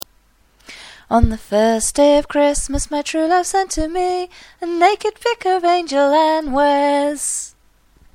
(Please note, that isn't me singing.